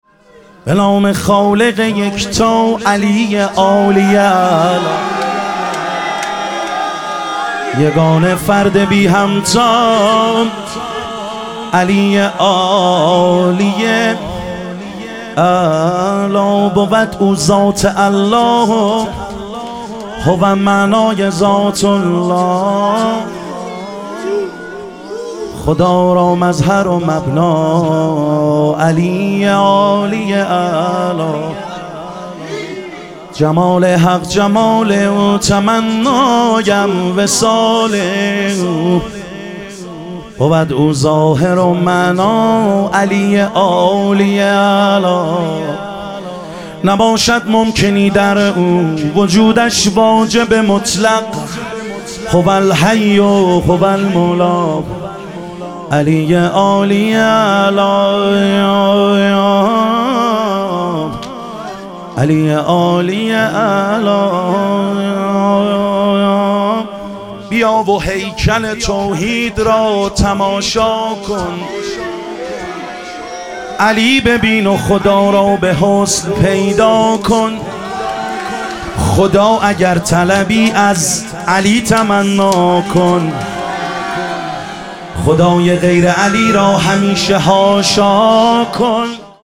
ظهور وجود مقدس امام جواد و حضرت علی اصغر علیهم السلام - مدح و رجز